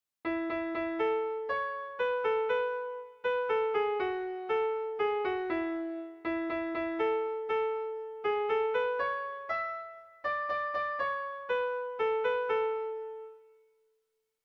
Irrizkoa
Kopla handia
ABD